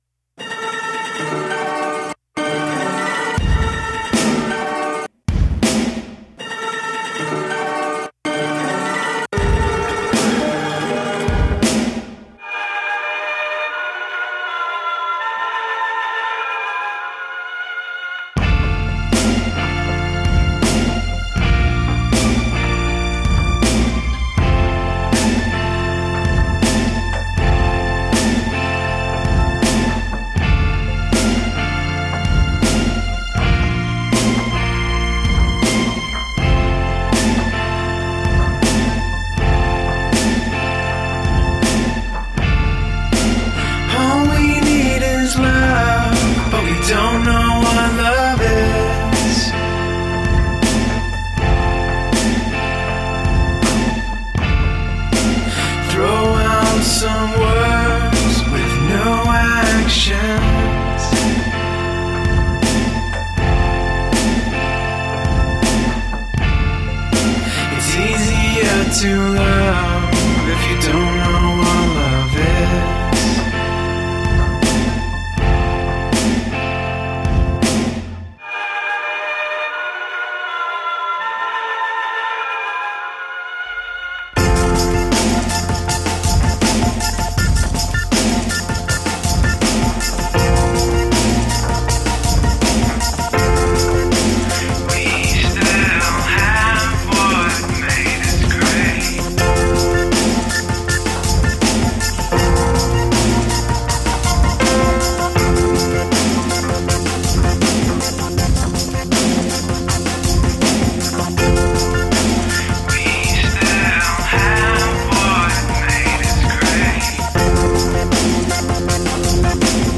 Tagged as: Electro Rock, Alt Rock, Prog Rock